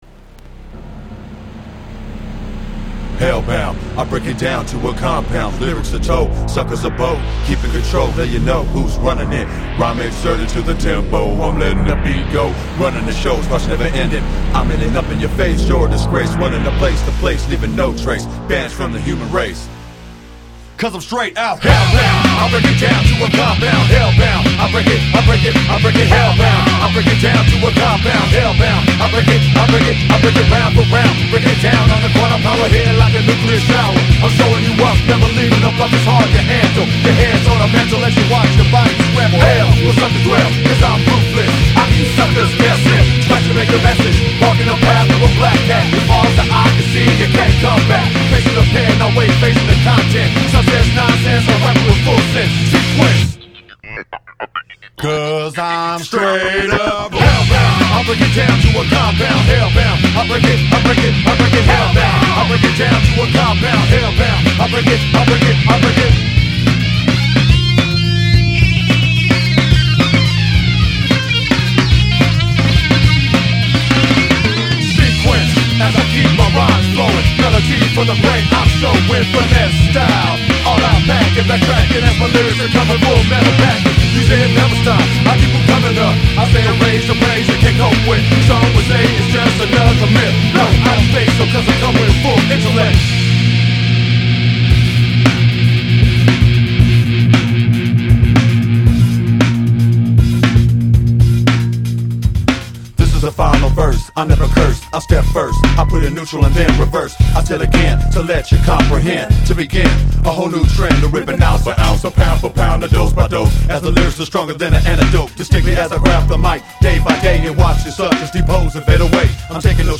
One of the heavier songs on the album.